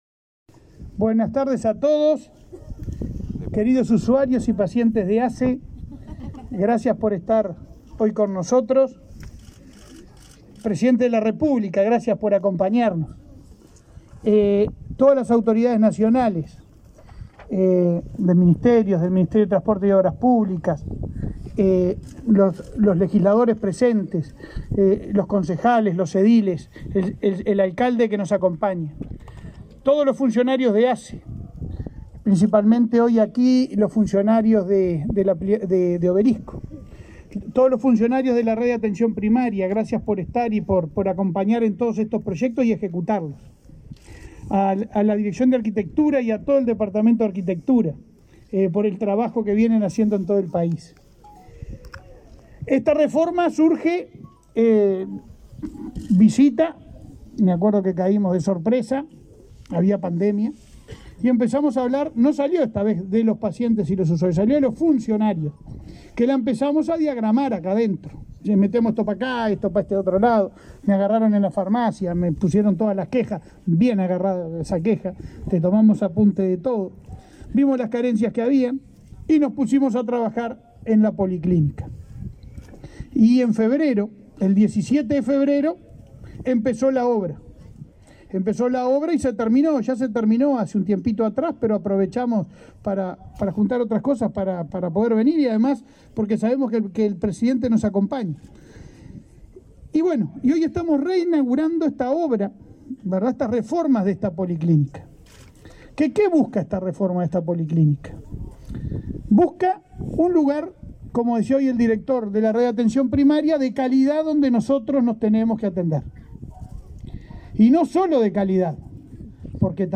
Palabras del presidente de ASSE, Leonardo Cipriani
Este 11 de agosto se inauguró una policlínica en el barrio Obelisco, en Las Piedras, departamento de Canelones.
El presidente de la Administración de los Servicios de Salud del Estado (ASSE) realizó declaraciones.